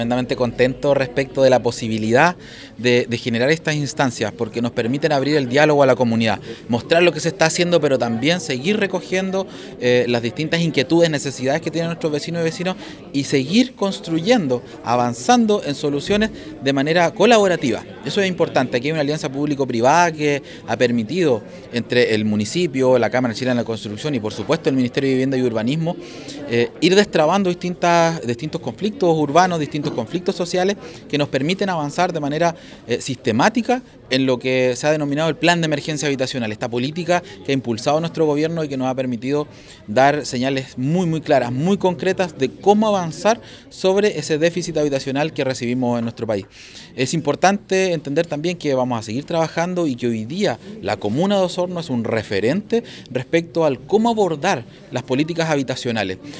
El Secretario Regional Ministerial de Vivienda y Urbanismo, Fabián Nail señaló que esta instancia permite abrir a la comunidad la información necesaria de cómo se abordan las políticas habitacionales.